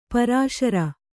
♪ parāśara